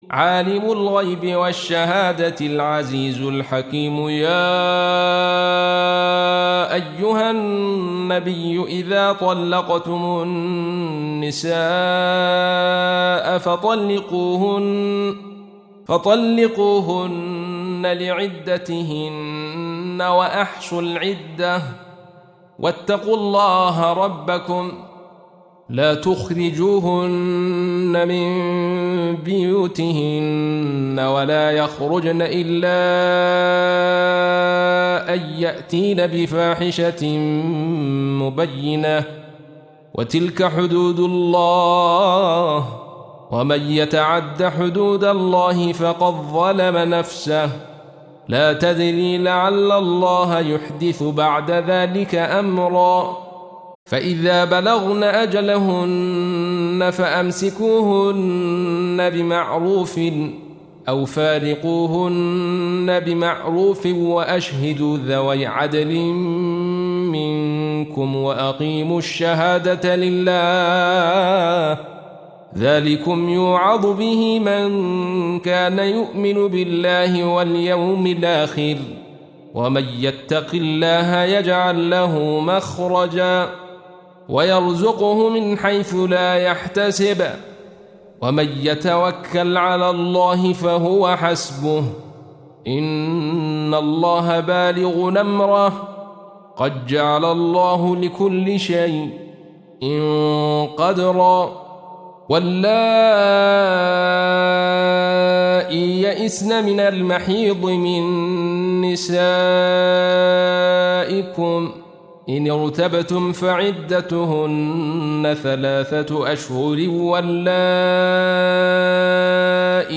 Surah Sequence تتابع السورة Download Surah حمّل السورة Reciting Murattalah Audio for 65. Surah At-Tal�q سورة الطلاق N.B *Surah Includes Al-Basmalah Reciters Sequents تتابع التلاوات Reciters Repeats تكرار التلاوات